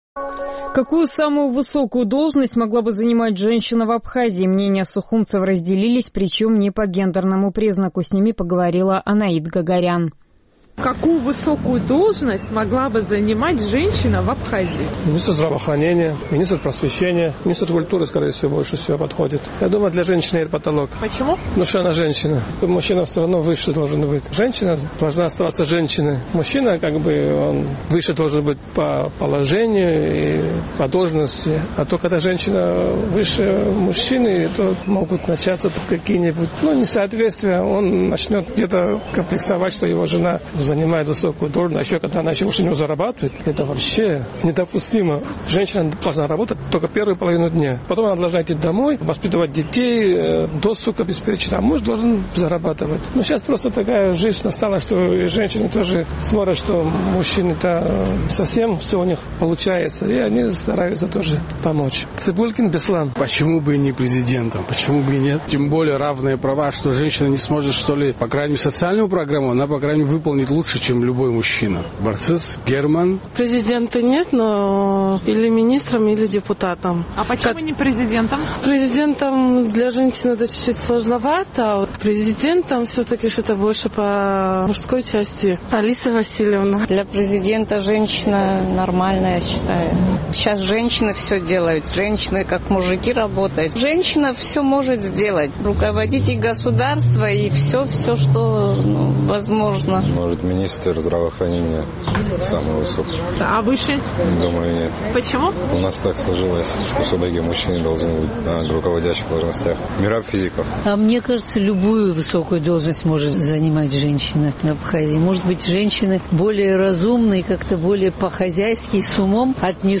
Наш сухумский корреспондент поинтересовалась у местных жителей, какую высокую должность могла бы занимать женщина в Абхазии.